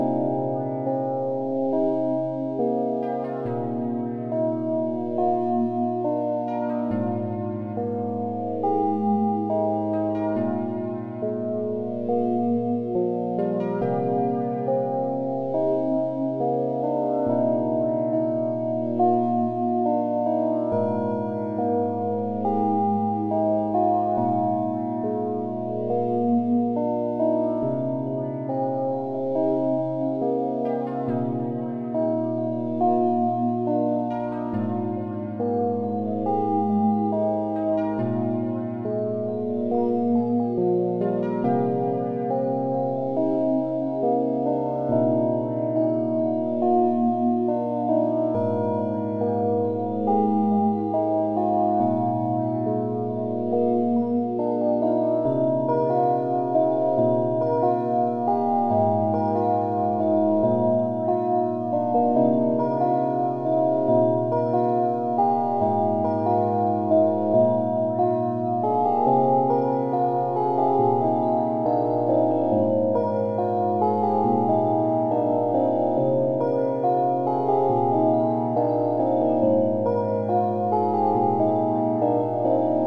A small space - ish fantasy music.